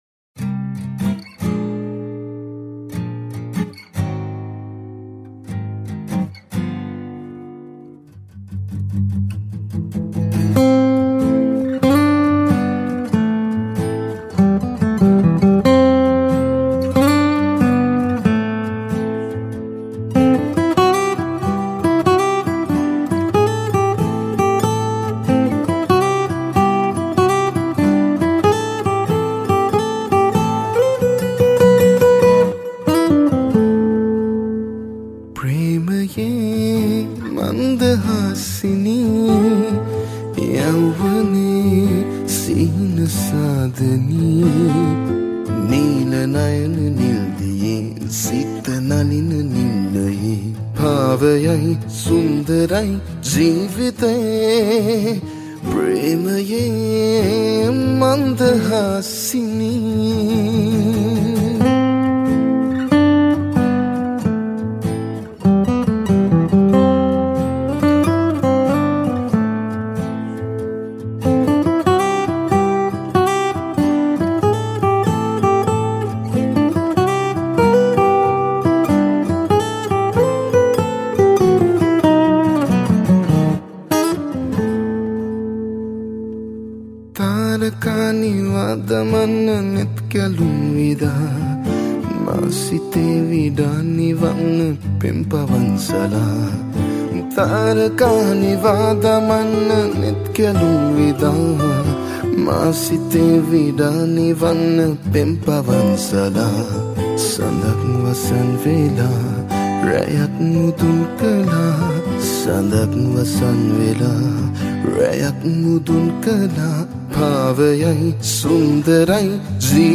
High quality Sri Lankan remix MP3 (84.5).